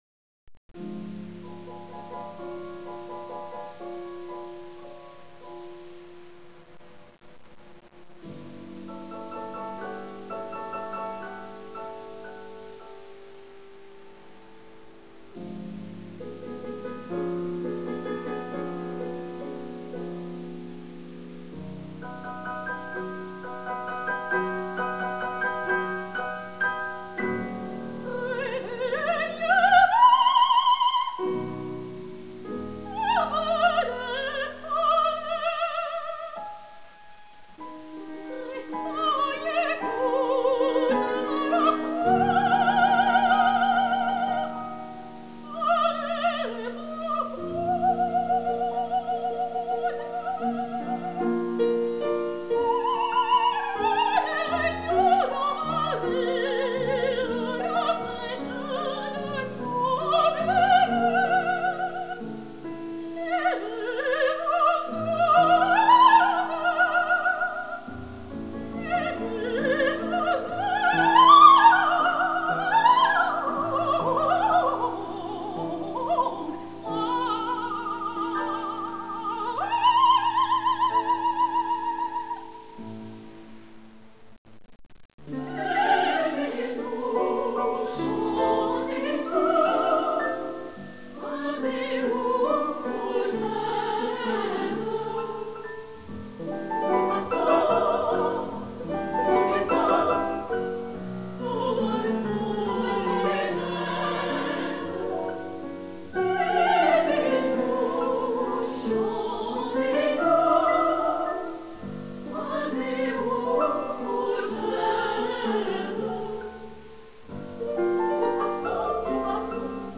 for soprano solo, Female Chorus and piano 4 hands.
pianos